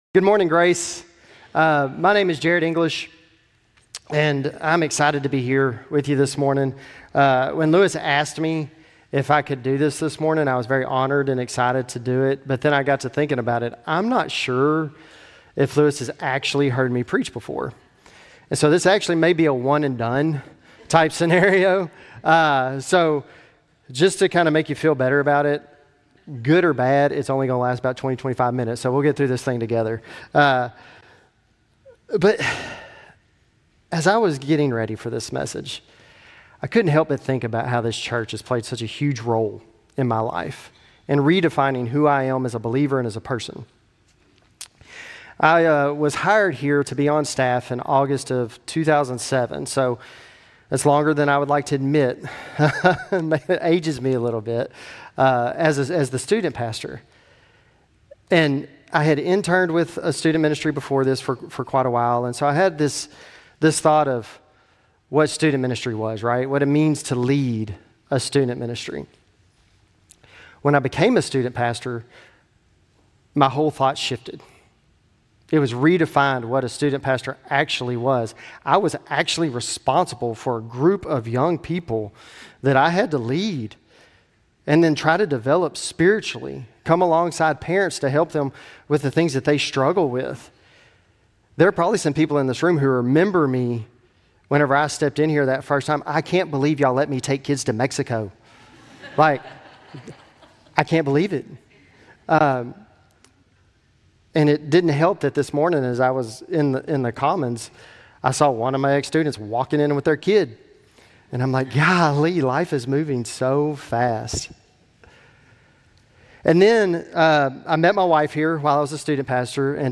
Grace Community Church Lindale Campus Sermons 2_9 Lindale Campus Feb 09 2025 | 00:22:03 1x 00:00 / 00:22:03 Subscribe Share RSS Feed Share Link Embed